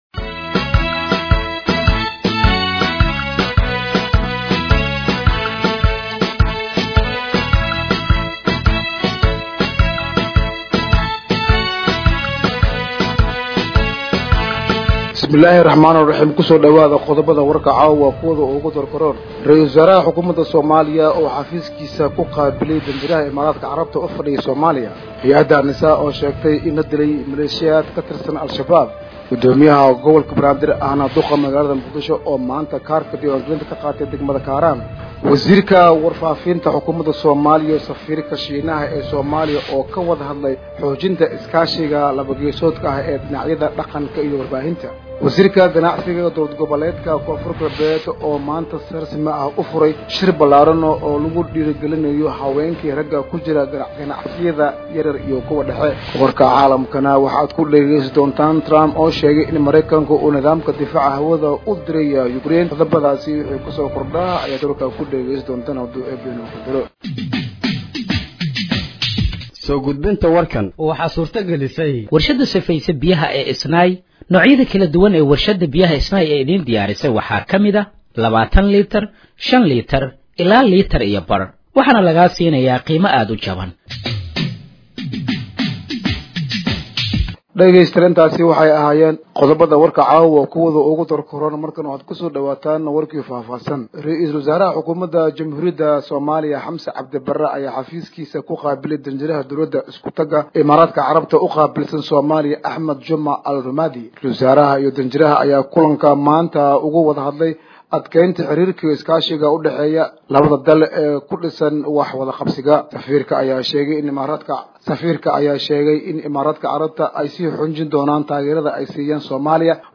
Dhageeyso Warka Habeenimo ee Radiojowhar 14/07/2025